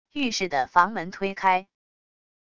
浴室的房门推开wav音频